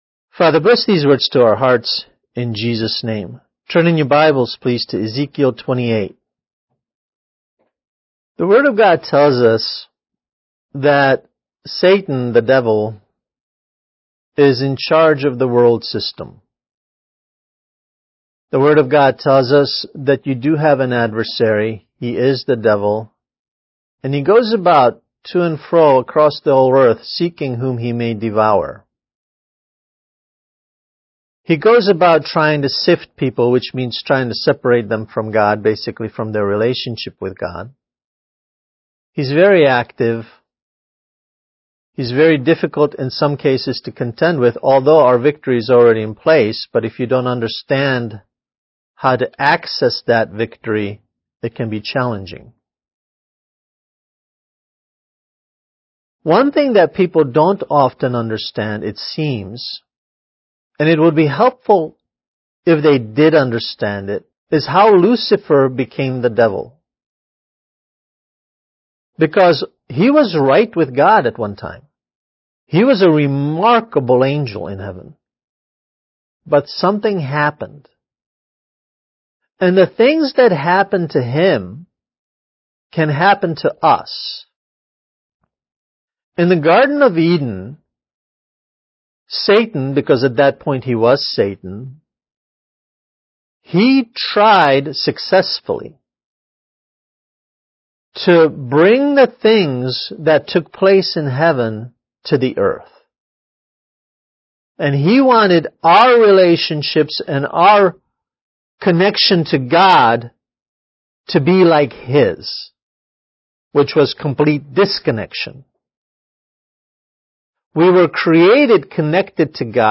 Kids Message: How Lucifer Became the Devil